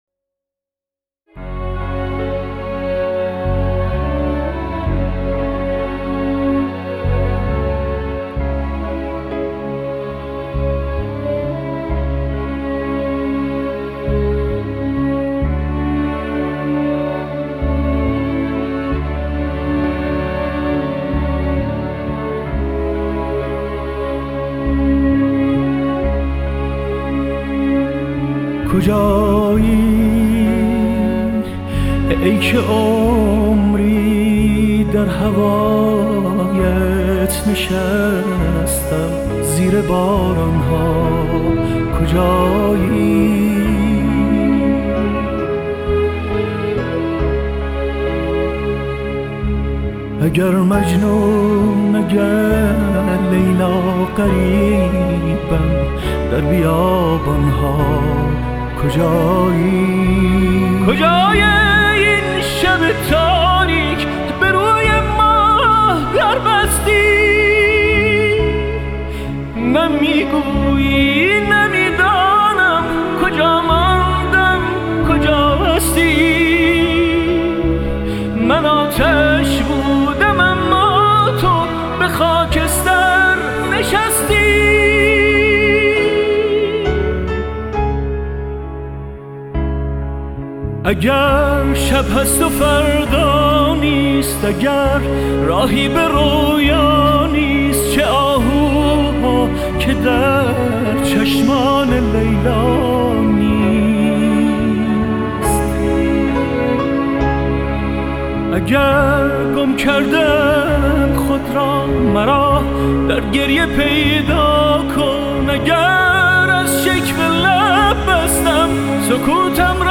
موزیک غمگین